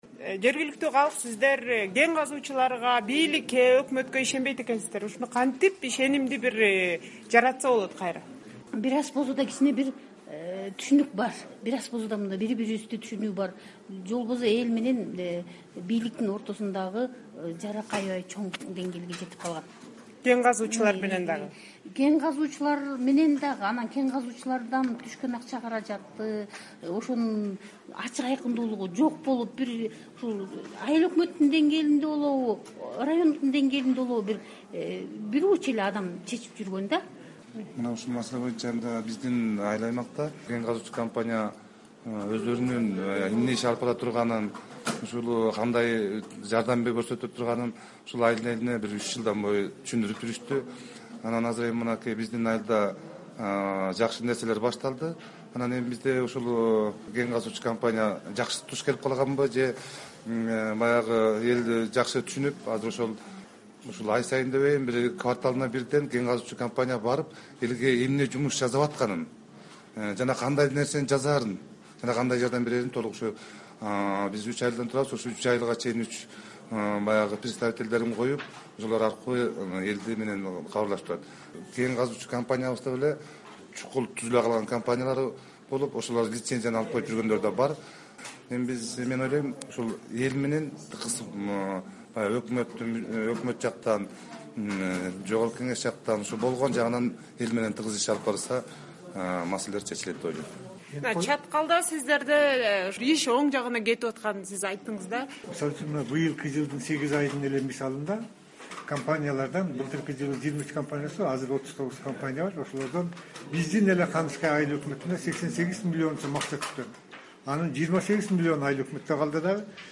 "Азаттыктын" талкуусу кенге байланышкан чыр-чатактарды чечүү жолдорун жергиликтүү тургундар кандай көрөт деген суроону талдайт.